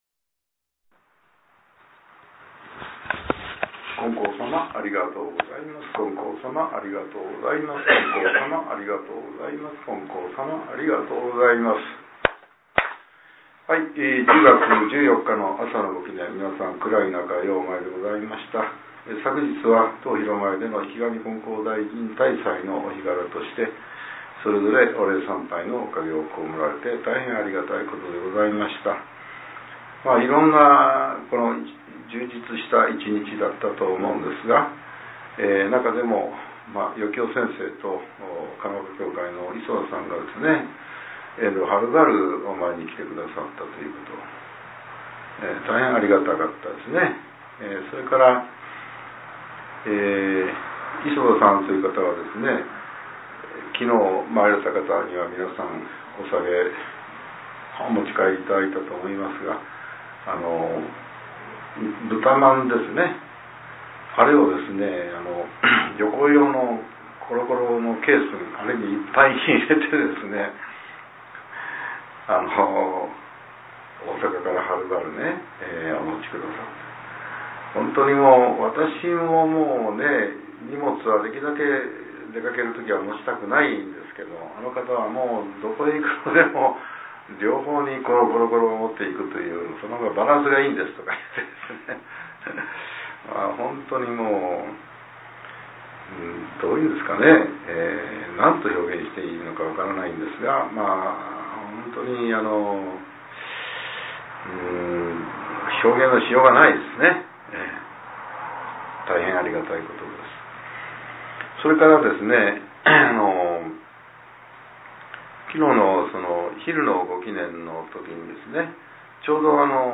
令和７年１０月１４日（朝）のお話が、音声ブログとして更新させれています。